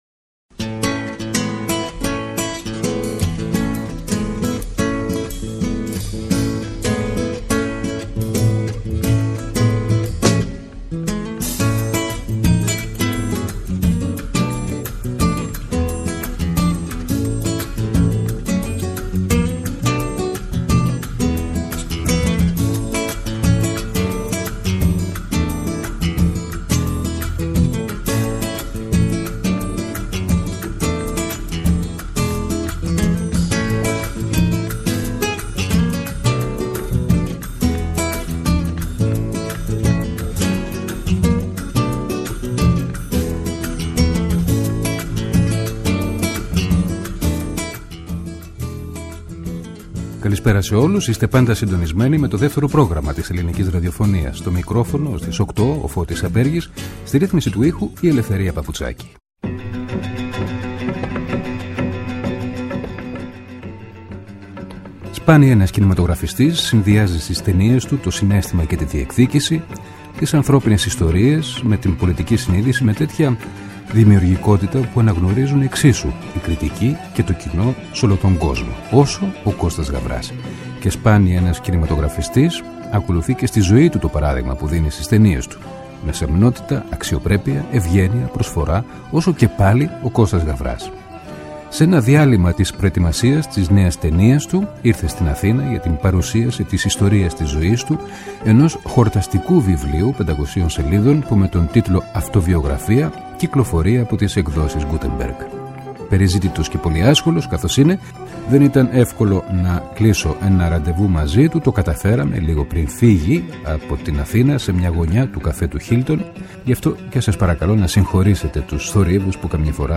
Ο Κώστας Γαβράς σε μια συνέντευξη- εξομολόγηση, που μεταδόθηκε σε δυο μέρη: τη Δευτέρα 10 και την Τρίτη 11 Δεκεμβρίου από το Δεύτερο Πρόγραμμα.